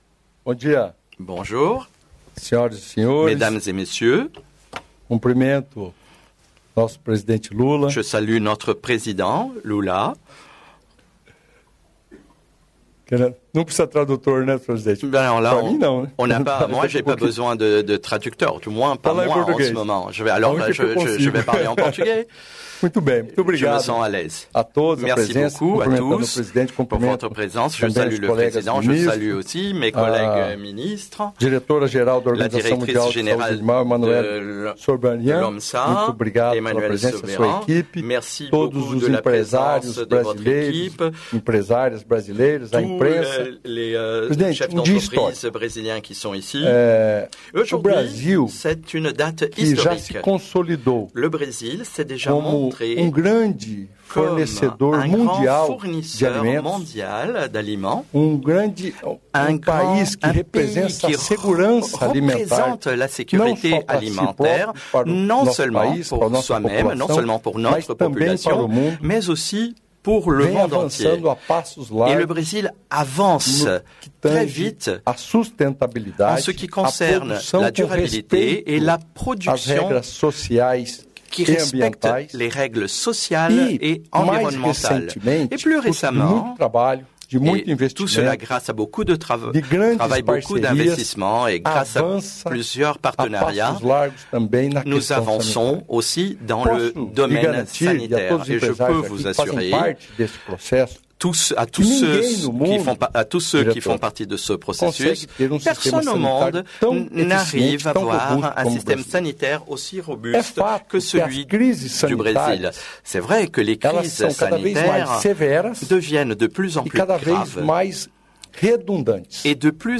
Íntegra do discurso do ministro da Agricultura e Pecuária, Carlos Fávaro, na cerimônia de certificação do Brasil como país livre da febre aftosa, nesta sexta-feira (6), em Paris.